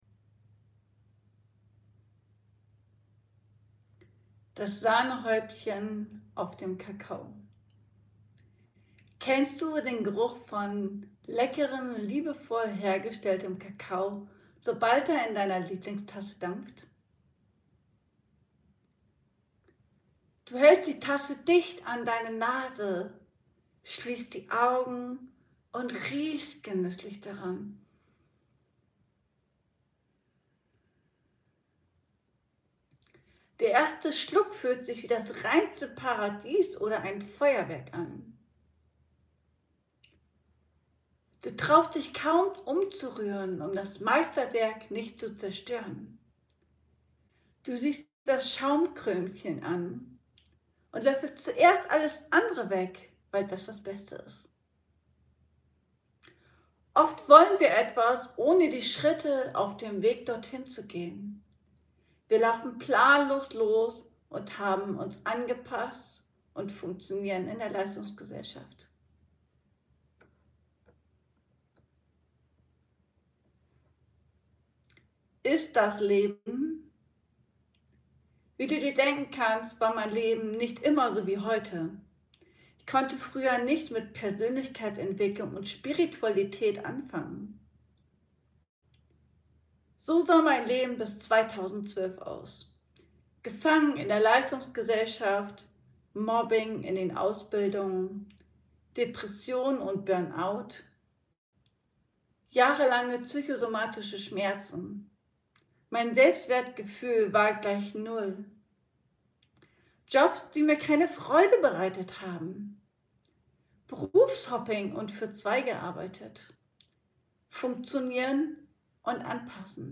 Der Weg zu meinem Herzen - Lesungsausschnitt ~ Ankommen lassen Podcast